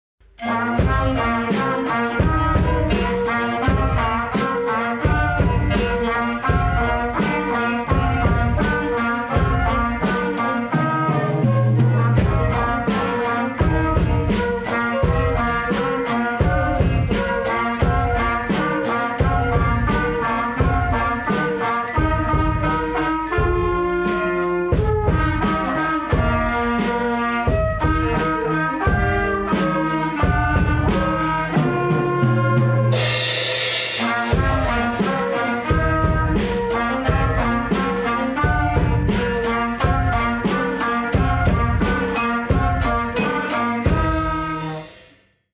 Osterständchen
Unsere Interpretation des spieltechnisch herausfordernden Arrangements erinnert stellenweise an einen Hühnerhaufen – wer genau hinhört, kann ab und zu dazwischen gackernde Hühner erkennen 😉
Osterständchen der LMG-Bläserklasse